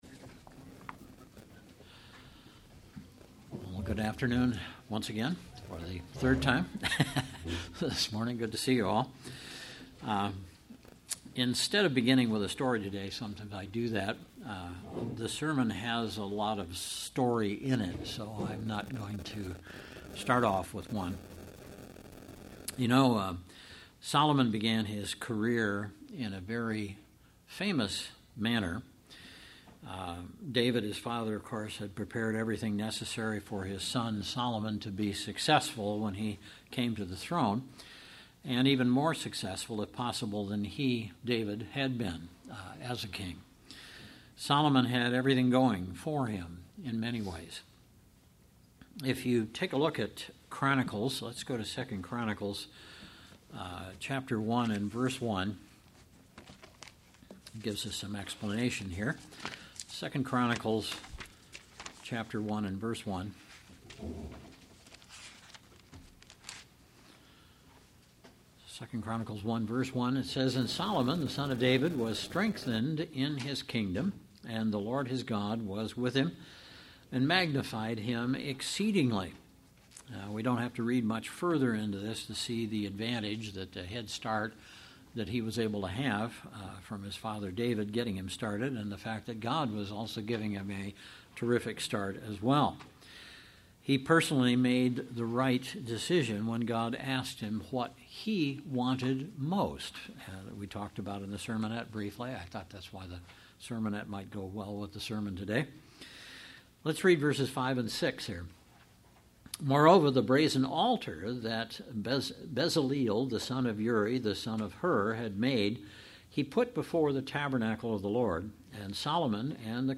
Sermons
Given in Central Illinois